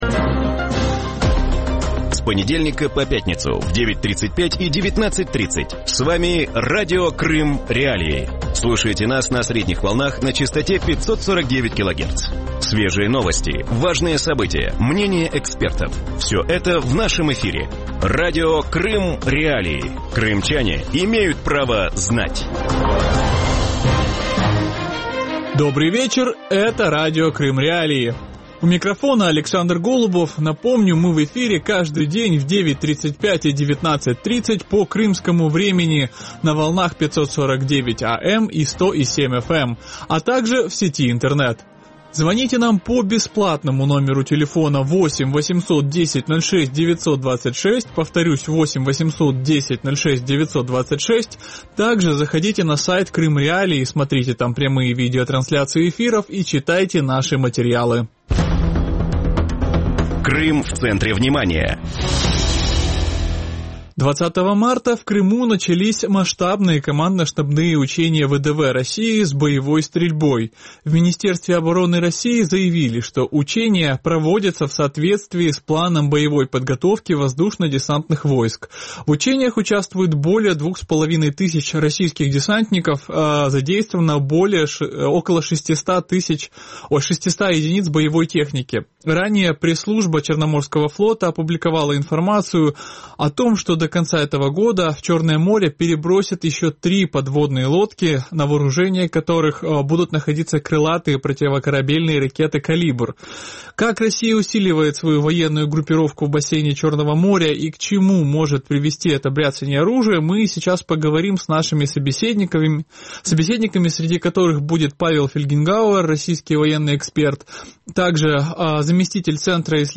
Під час навчань у анексованому Криму російські десантники відпрацьовували наступальні бойові дії, які потенційно можуть бути використані у напрямку материкової України. Таку думку у вечірньому ефірі Радіо Крим.Реалії висловив російський військовий експерт Павло Фельгенгауер.